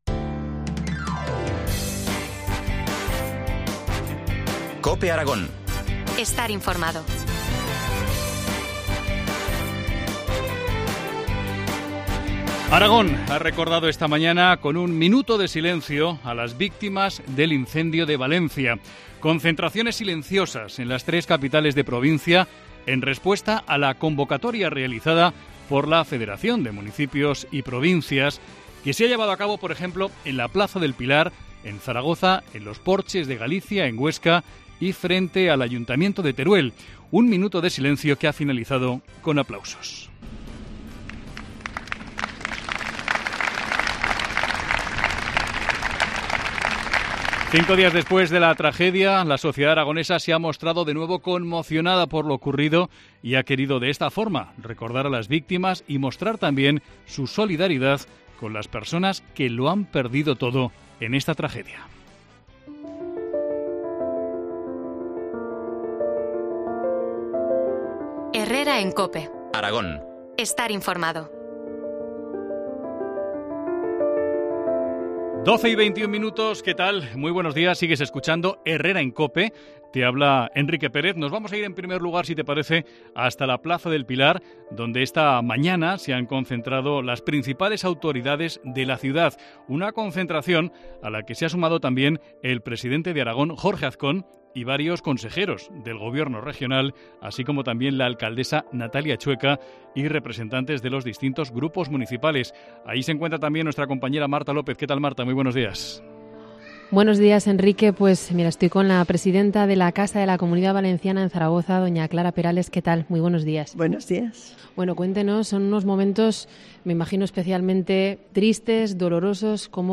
Entrevista del día en COPE Aragón